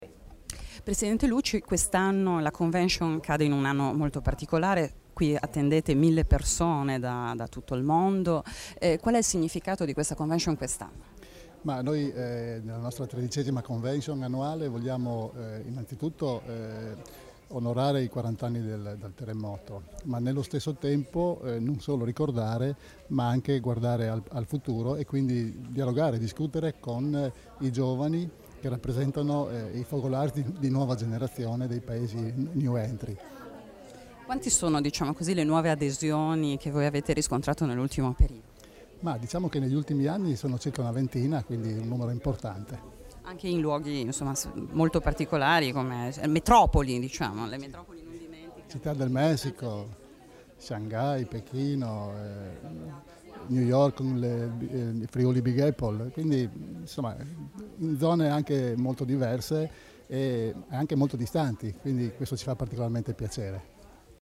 Dichiarazioni di Debora Serracchiani (Formato MP3) [1498KB]
alla tredicesima Convention annuale dei Friulani nel Mondo intitolata quest'anno, nel quarantesimo delle celebrazioni per il terremoto del 1976, "Quando la terra chiama: il Friûl nol dismentee", rilasciate a San Daniele del Friuli il 30 luglio 2016